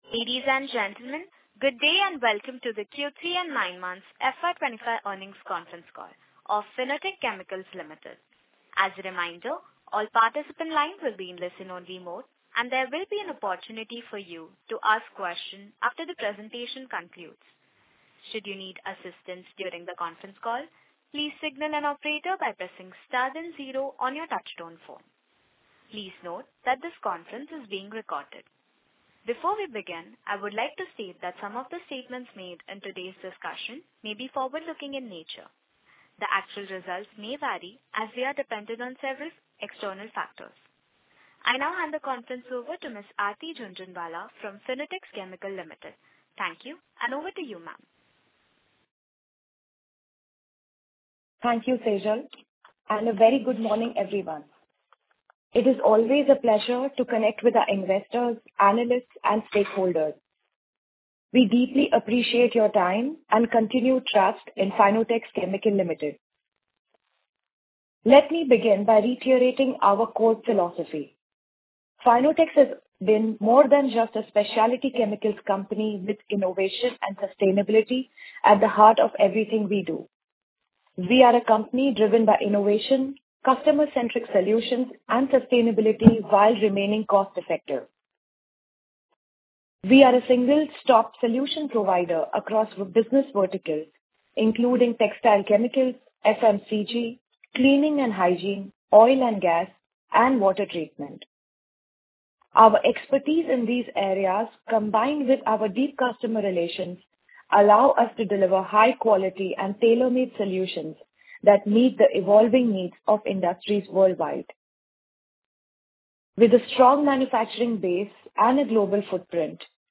Concalls
Concall-Q3-FY25-Audio-Recording.mp3